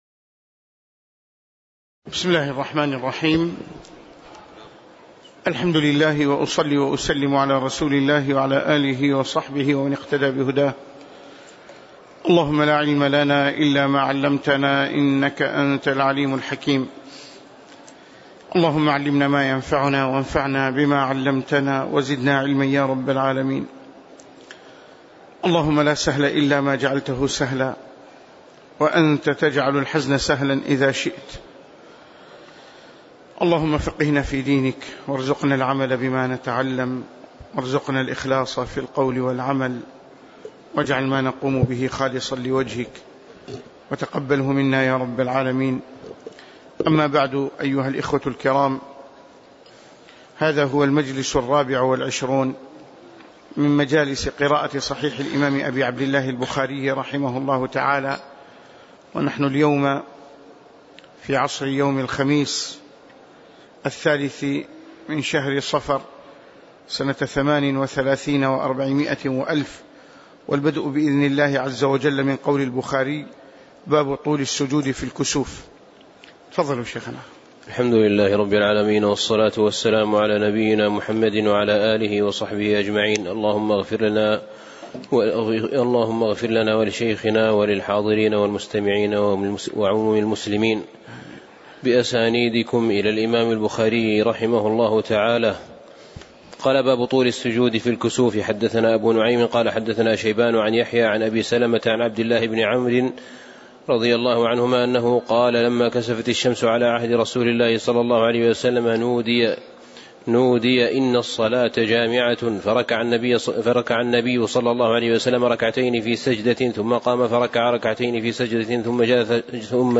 تاريخ النشر ٣ صفر ١٤٣٨ هـ المكان: المسجد النبوي الشيخ